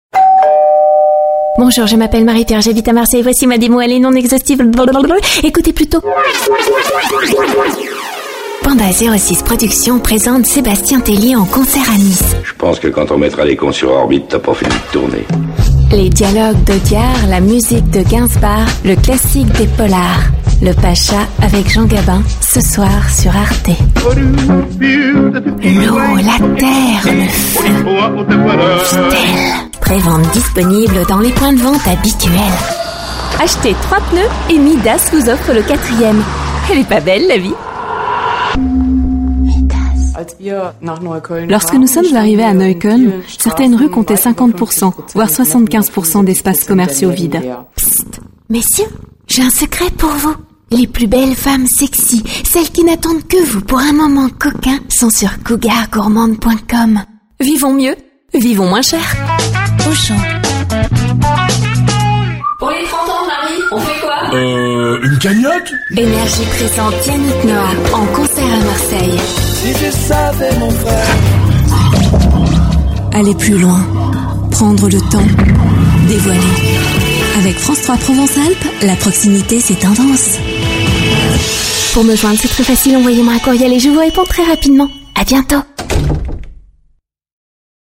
Voix de femme en français ⋆ Domino Studio